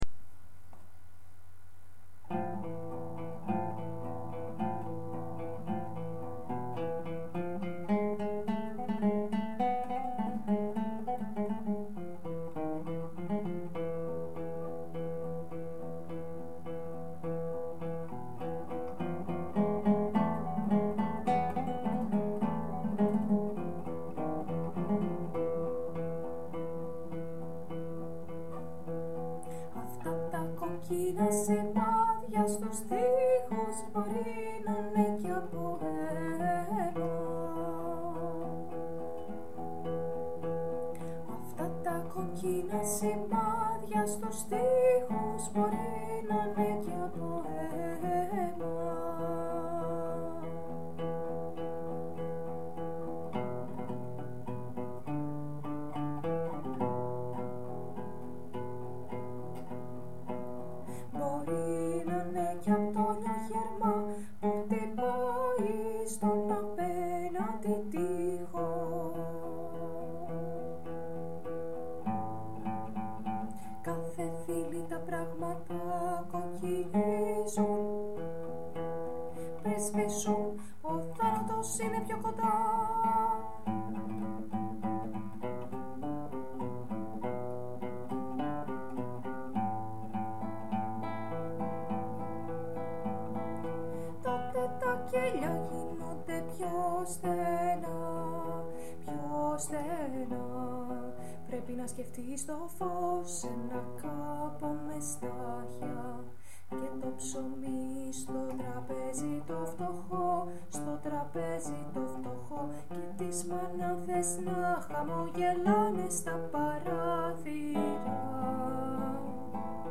MP3 ΓΙΑ ΕΞΑΣΚΗΣΗ ΤΩΝ ΧΟΡΩΔΩΝ
ΡΙΤΣΟΣ 1η ΦΩΝΗ